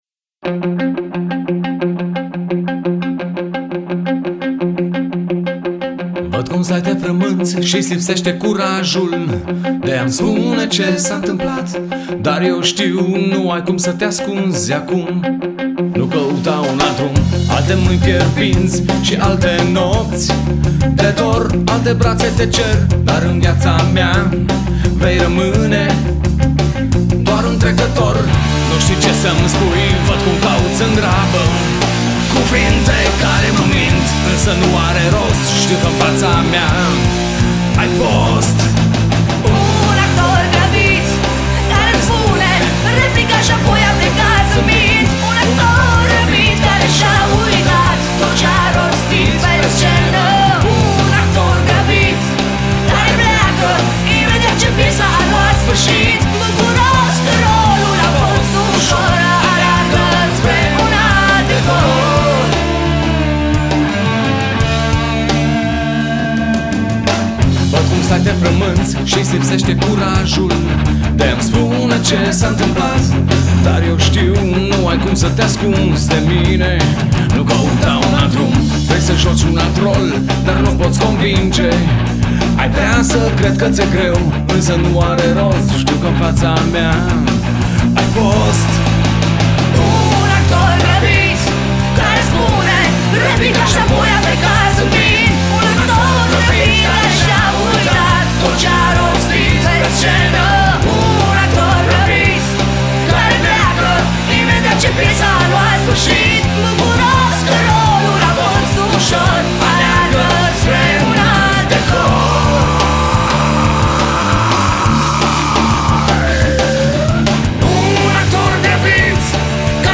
a makeshift punk outfit from Bucharest